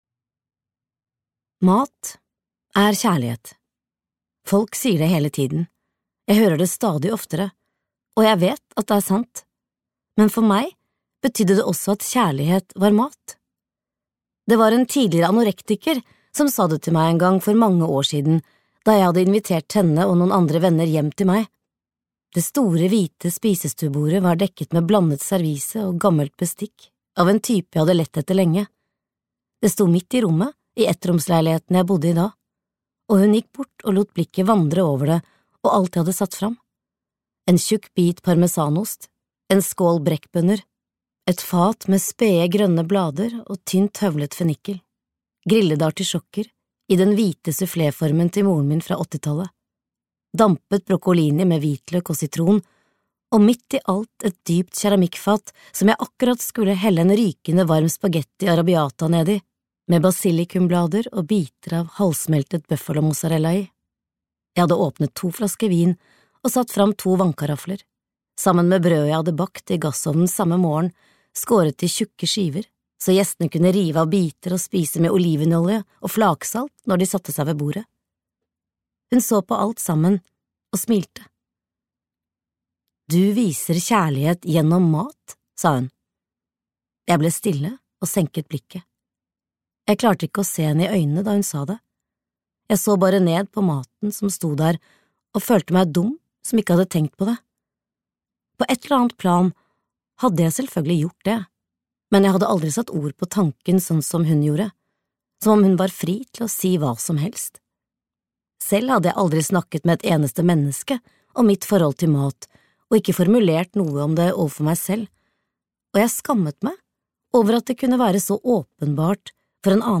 Brød og melk (lydbok) av Karolina Ramqvist